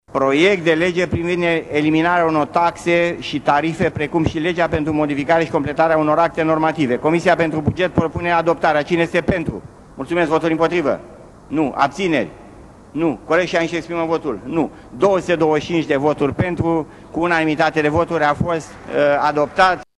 Președintele de ședință a anunțat că proiectul a fost votat în unanimitate: